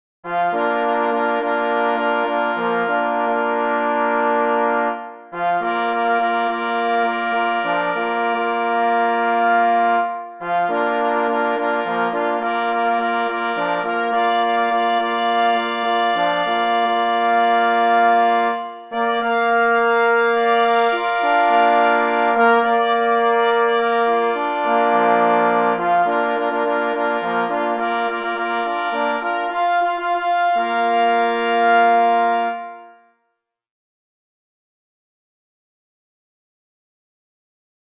B. Rup – Hejnał Festiwalowy – Wałbrzych 2002 – na 2 plesy i 2 parforsy | PDF